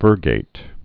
(vûrgāt)